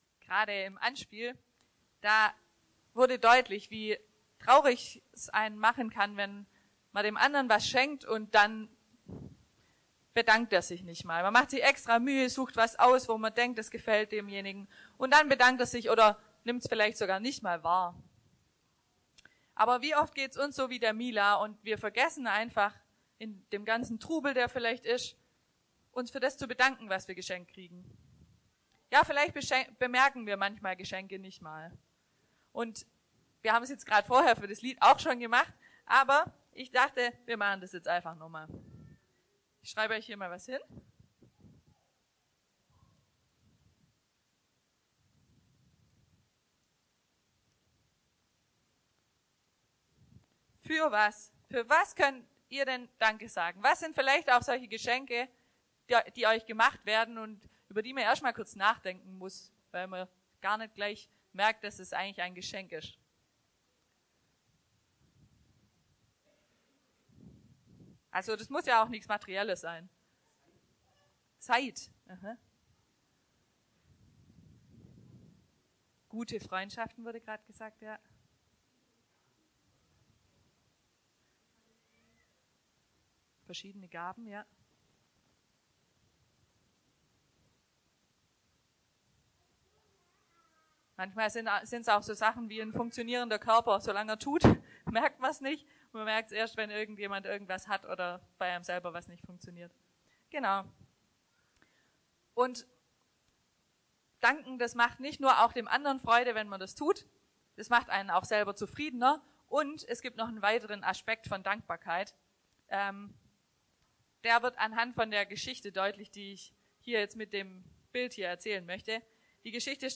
Dankbarkeit ~ Predigten aus der Fuggi Podcast